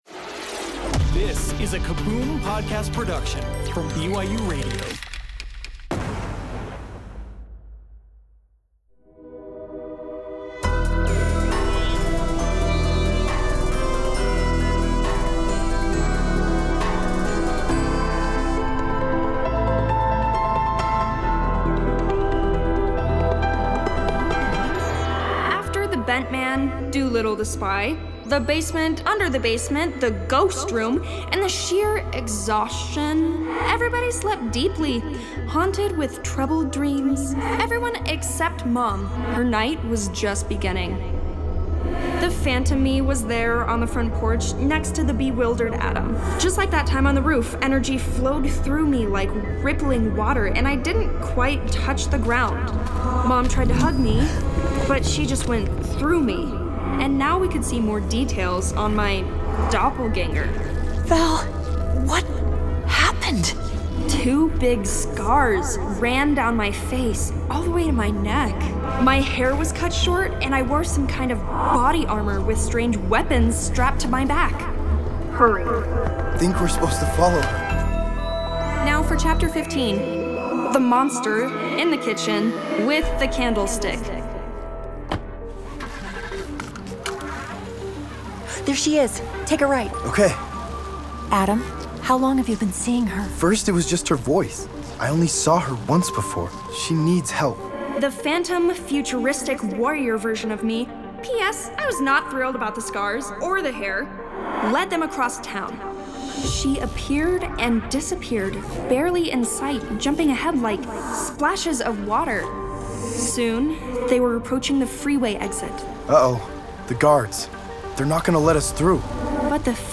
Bedtime Stories Audio Drama BYUradio Sci-Fi / Fantasy Stories Content provided by BYUradio.
OlympusDaleEp15StereoMix.mp3